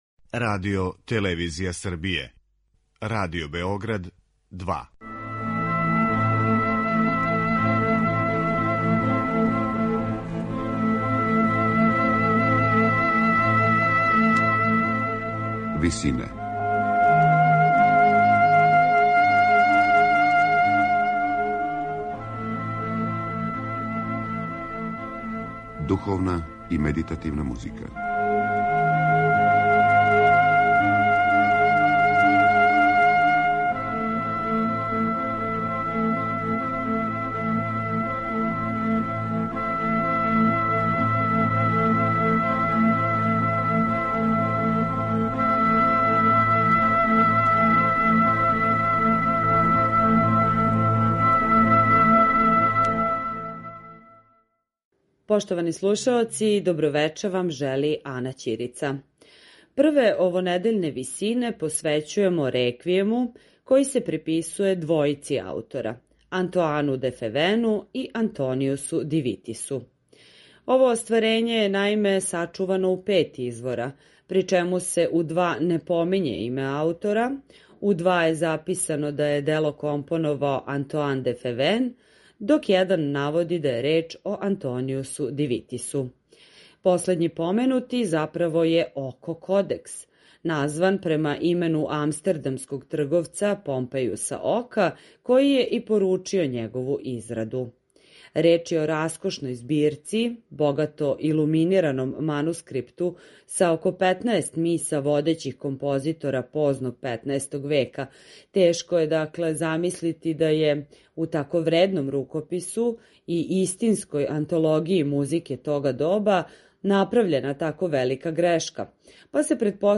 Реквијем
медитативне и духовне композиције